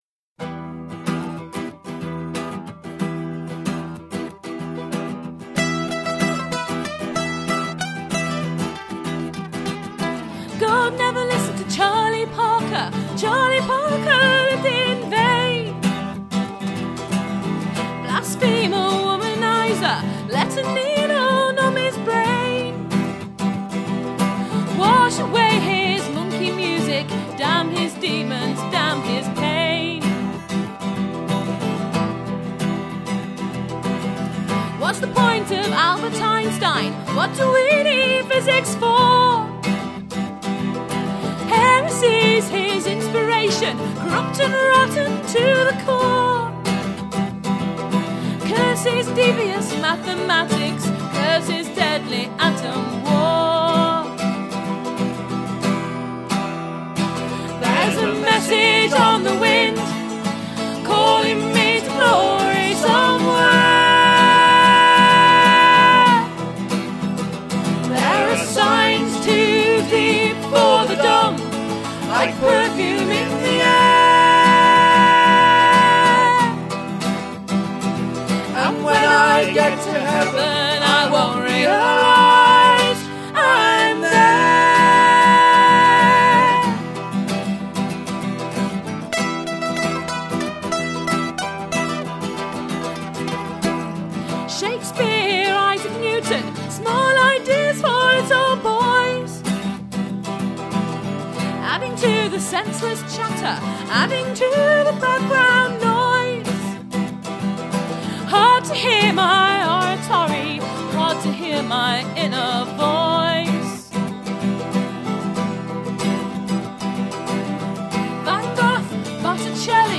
folk based contemporary acoustic trio